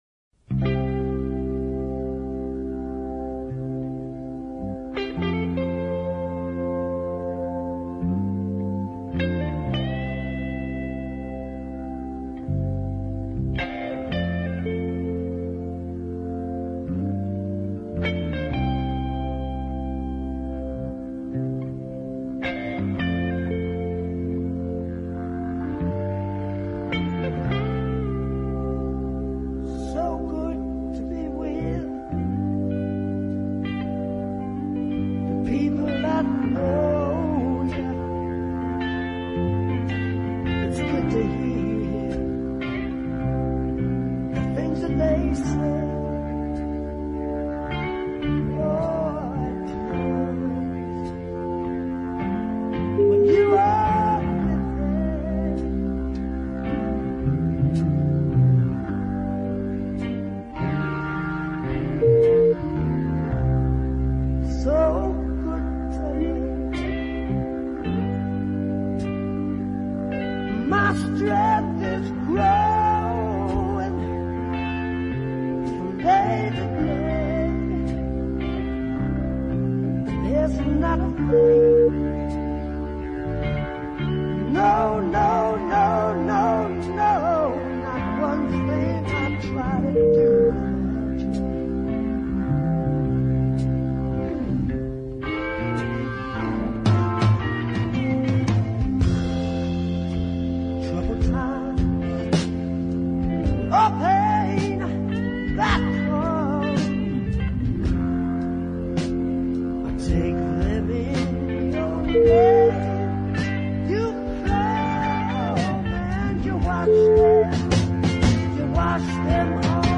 And the instrumental workout at the end screams "MGs".